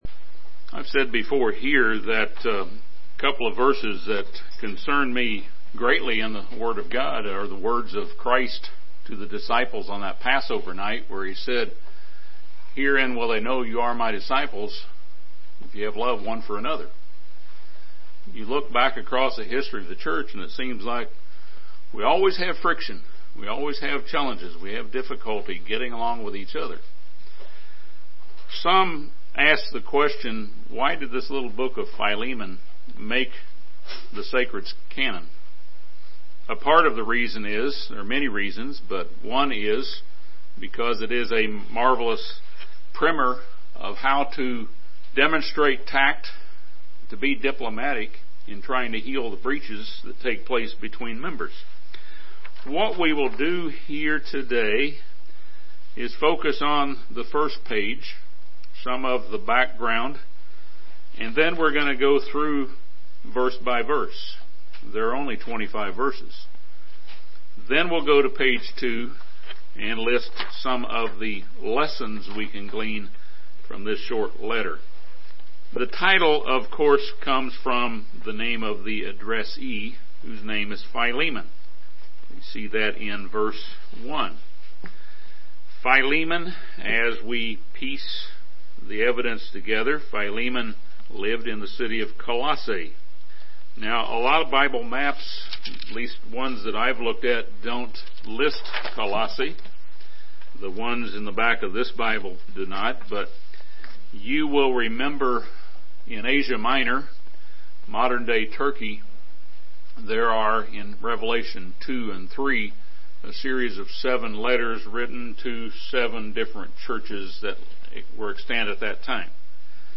This sermon discusses 8 lessons from Philemon that can help improve our ability to get along with others.